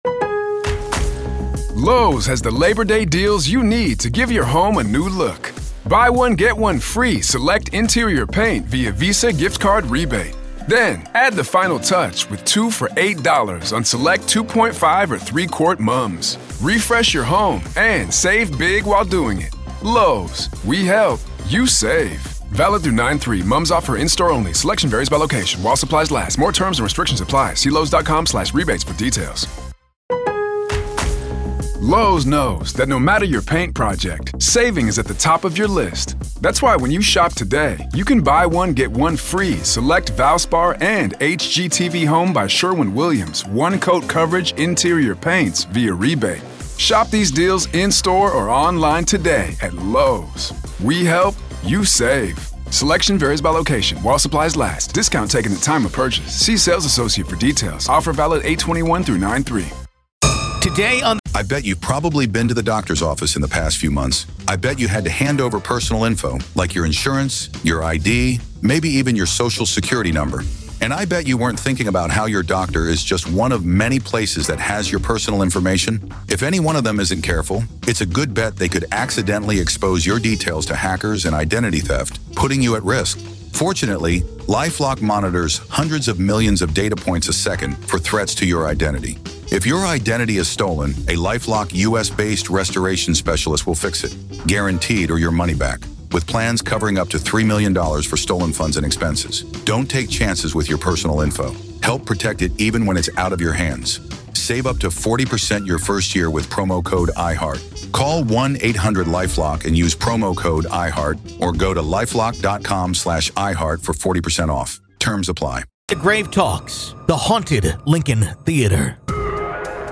To listen to part two of our interview, you need to be a Grave Keeper (supporter of the show).